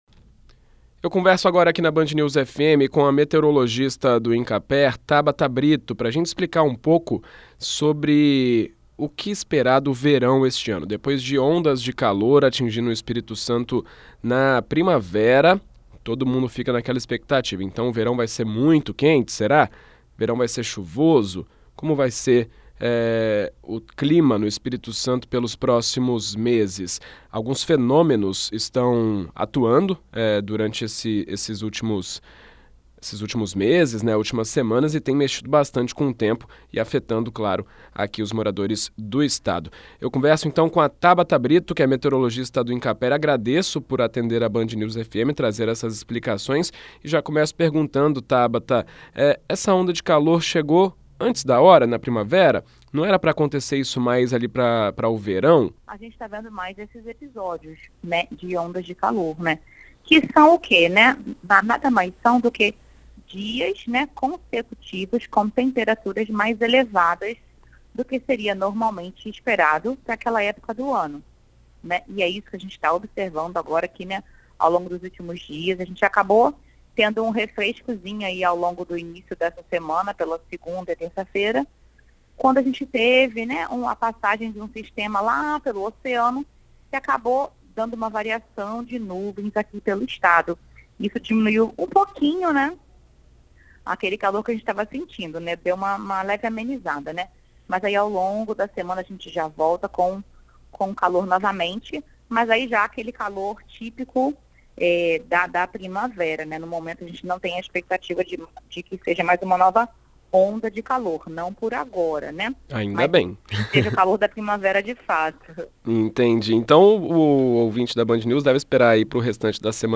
Em entrevista a BandNews FM Espírito Santo